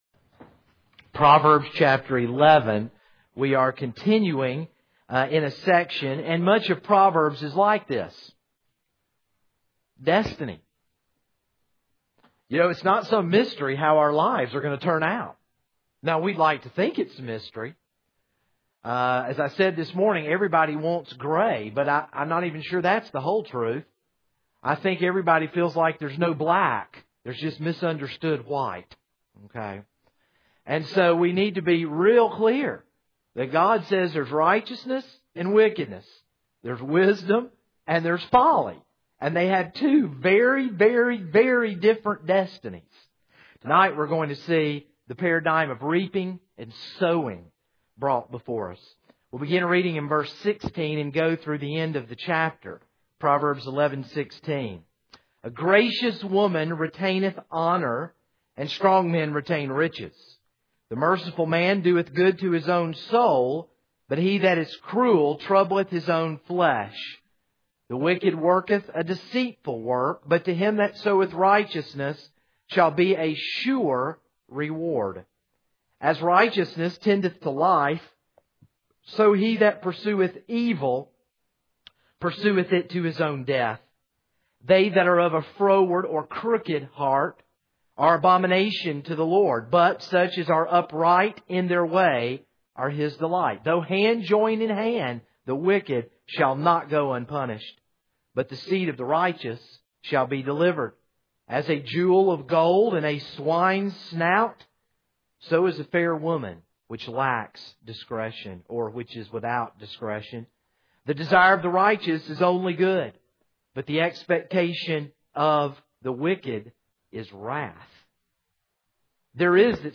This is a sermon on Proverbs 11:16-31.